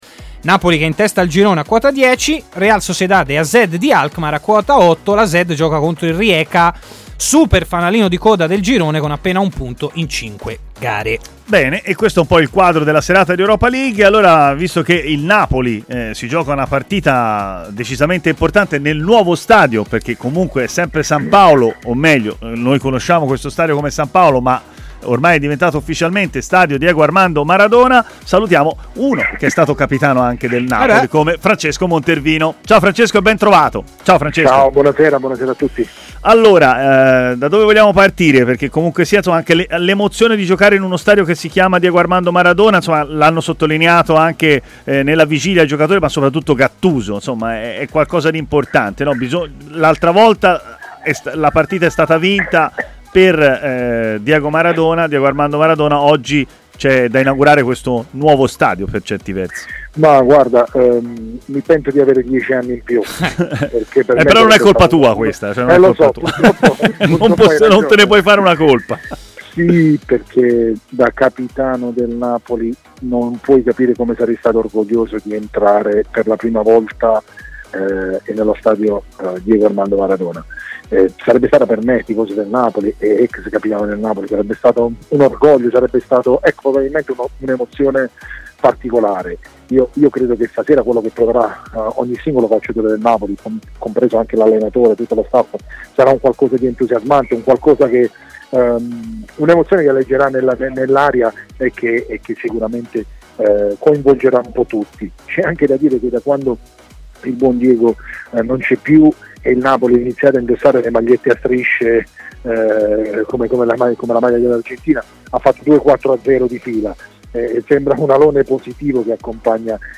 intervenuto in diretta nel corso di Stadio Aperto, trasmissione di TMW Radio